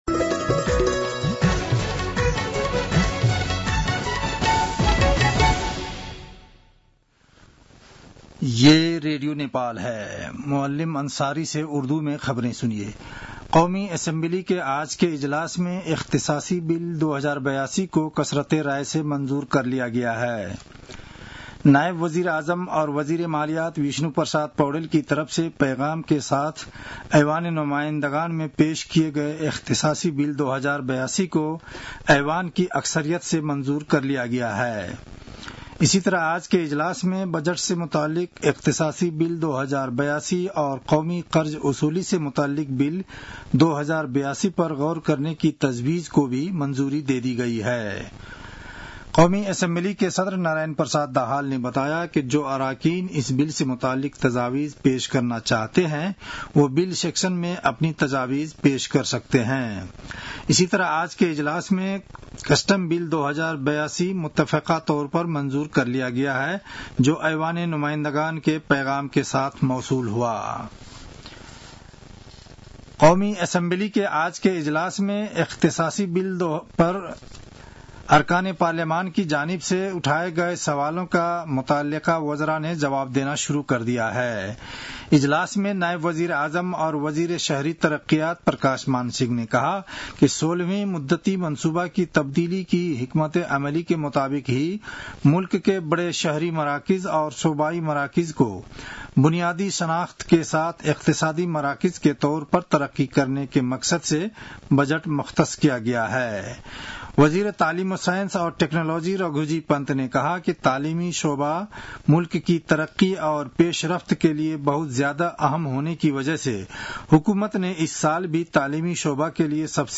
उर्दु भाषामा समाचार : १८ असार , २०८२